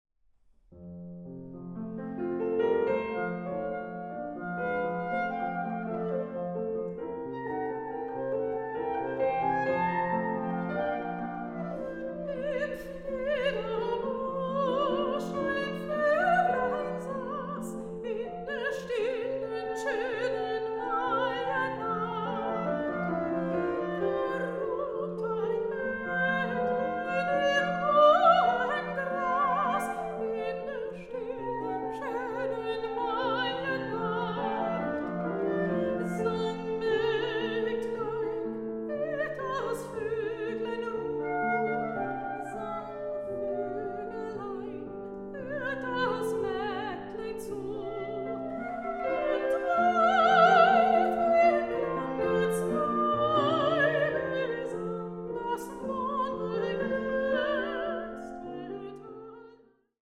Soprano
Clarinet
Piano
Recording: Tonstudio Ölbergkirche, Berlin, 2023